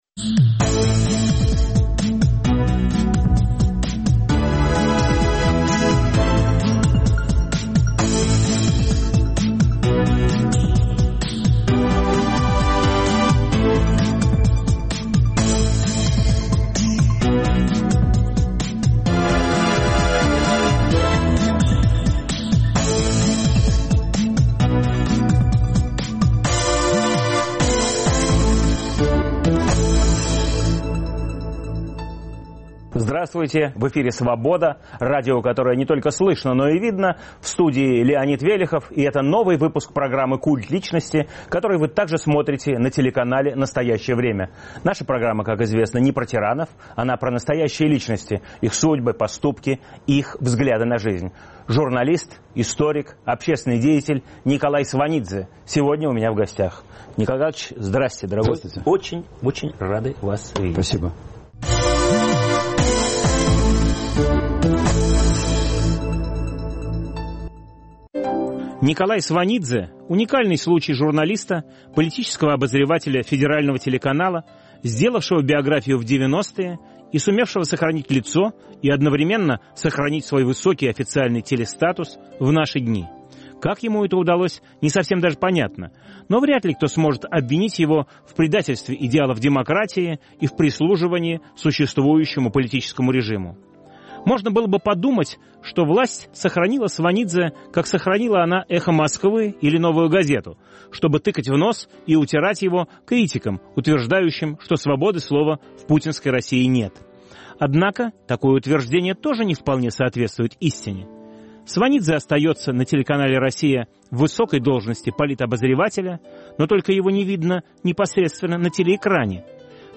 В студии нового выпуска "Культа личности" журналист Николай Сванидзе. Автор и ведущий - Леонид Велехов.